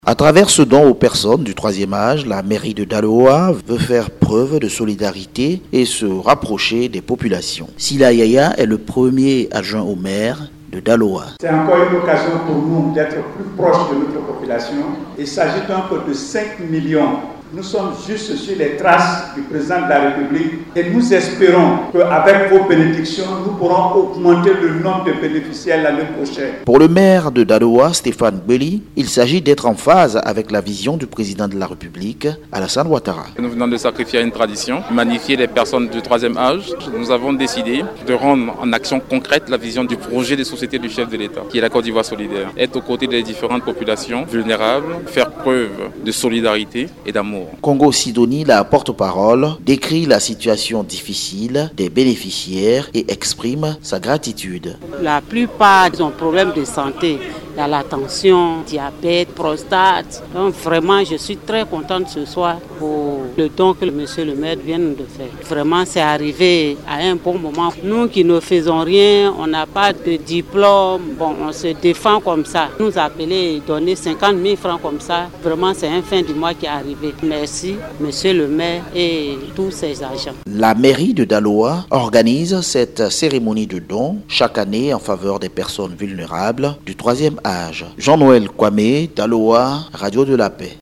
Compte rendu